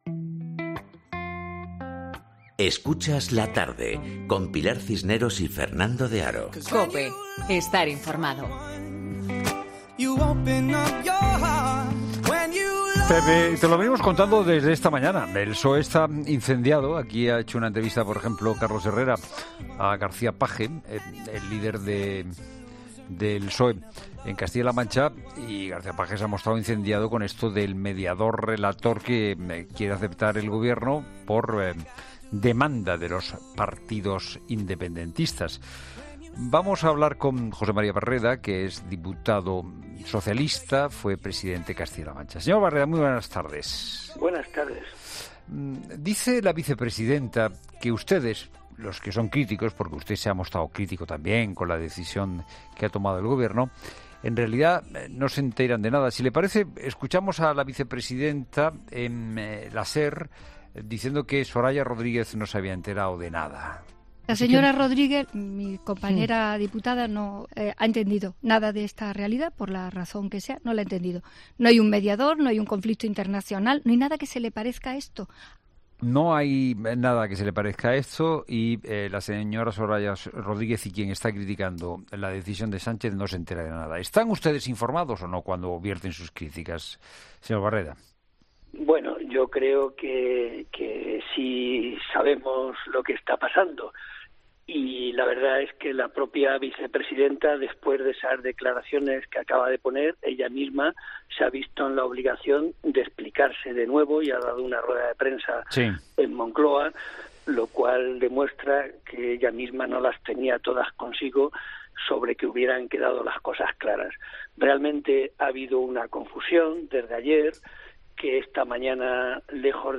El presidente de la Junta de Comunidades de Castilla-La Mancha, ha criticado en los micrófonos de La Tarde de COPE la decisión del Ejecutivo Nacional de establecer la figura del “relator” en la mesa de diálogo entre el Gobierno y la Generalitat, en concesión a las reclamaciones de los indepentistas de designar un mediador internacional.